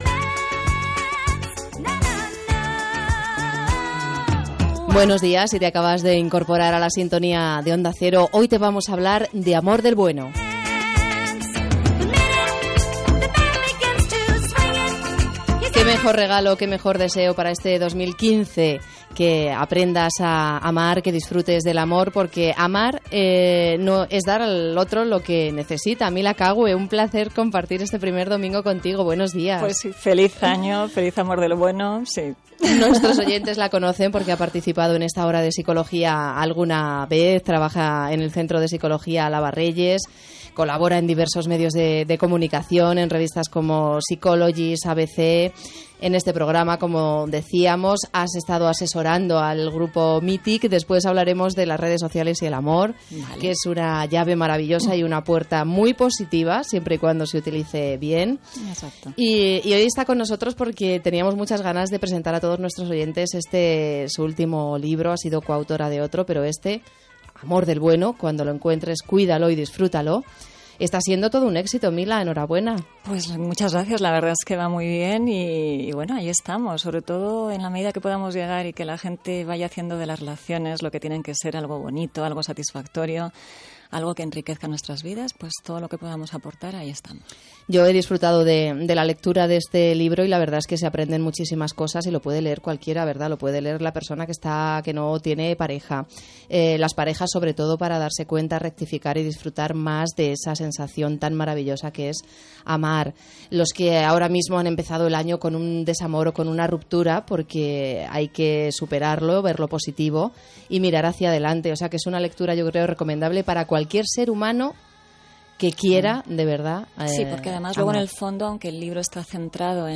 en el estudio de grabación de Onda Cero